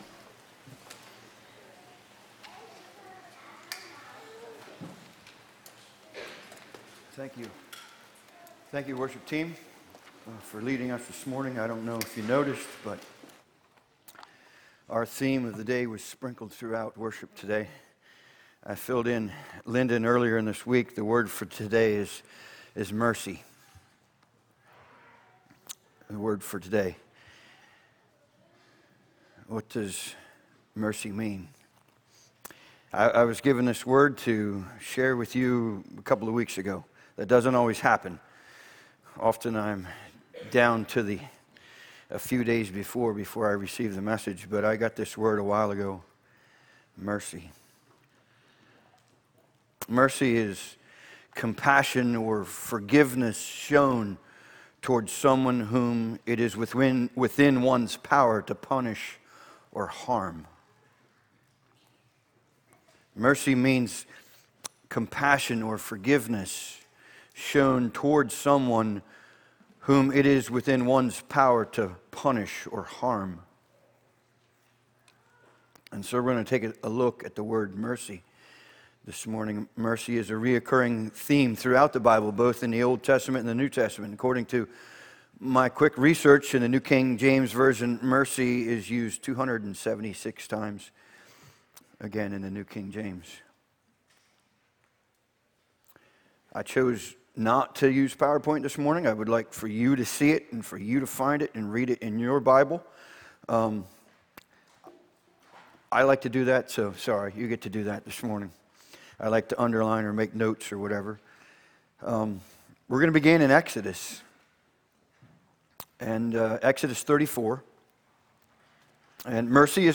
Sermon Archive | - New Covenant Mennonite Fellowship
From Series: "Sunday Morning - 10:30"